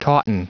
Prononciation du mot tauten en anglais (fichier audio)
Prononciation du mot : tauten